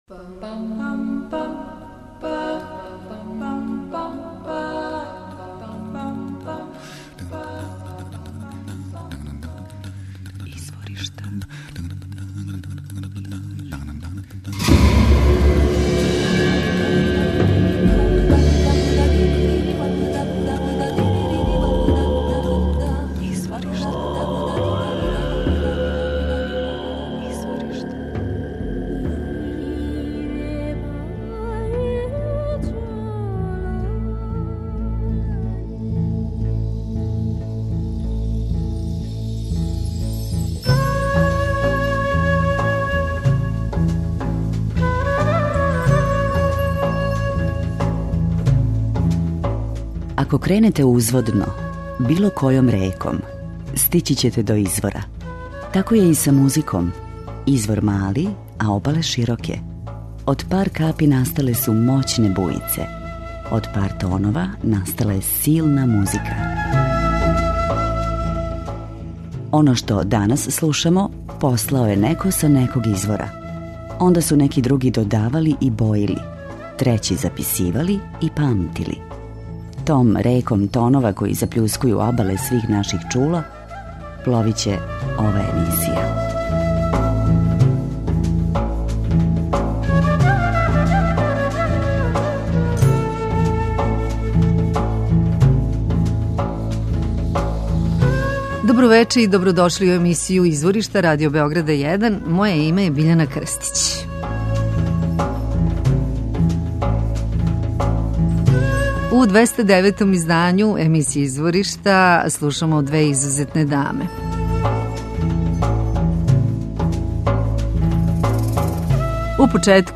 Она пева, свира гитару, нгони и балафон.